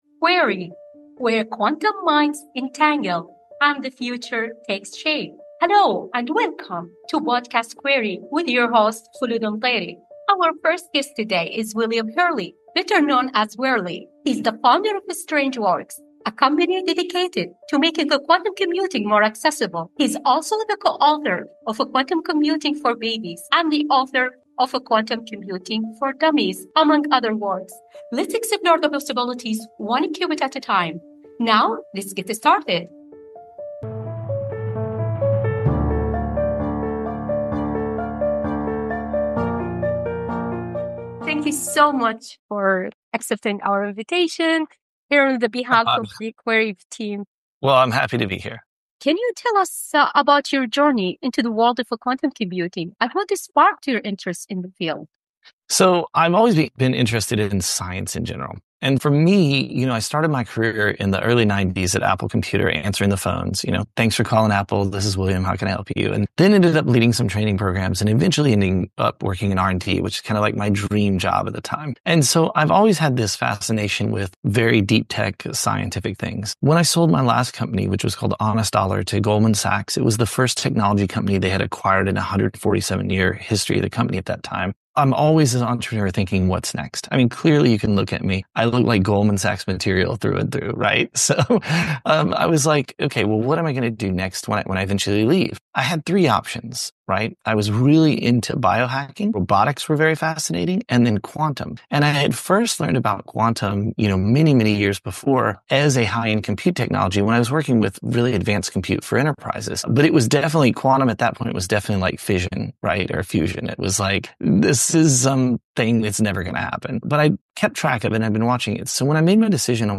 Join us for a compelling conversation with a visionary quantum entrepreneur.